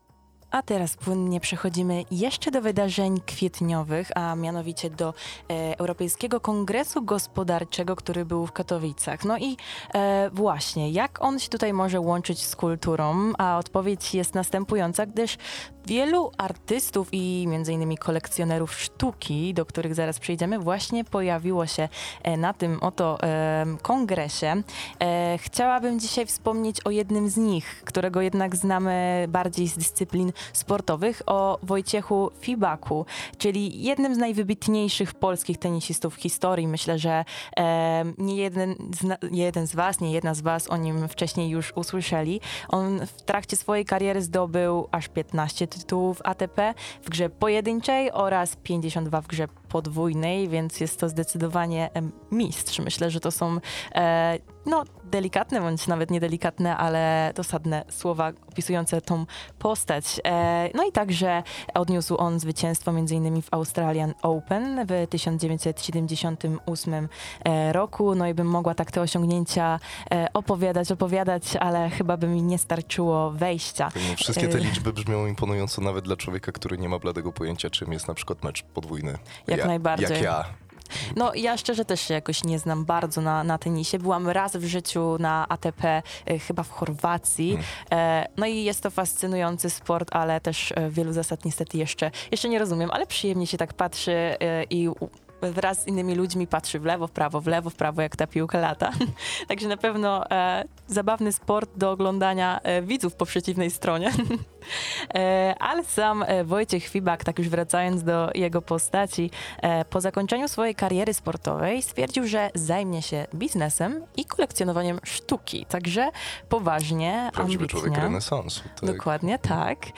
To właśnie o sztuce, kulturze oraz jej relacji z biznesem Wojciech Fibak opowiadał podczas XVII Europejskiego Kongresu Gospodarczego w Katowicach.